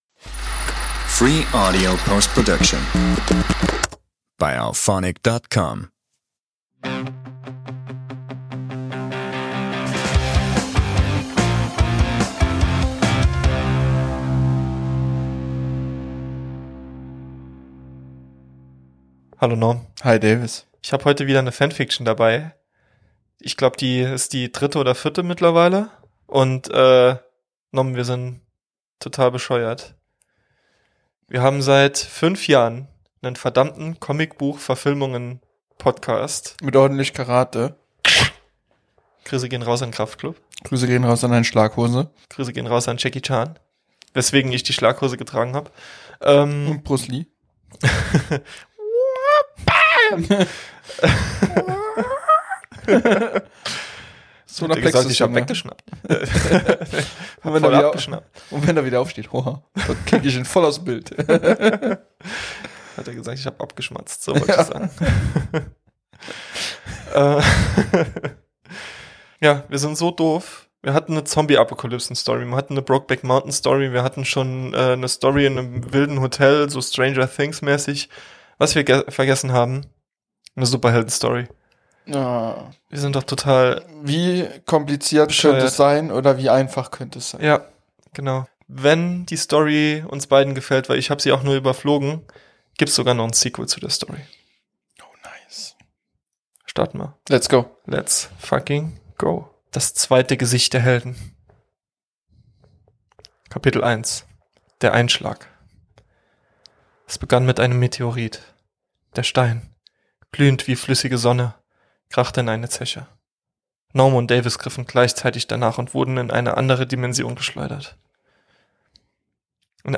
In dieser Episode wird’s wieder richtig cineastisch: Wir lesen eine brandneue Fan Fiction vor – und diesmal sogar mit Sequel!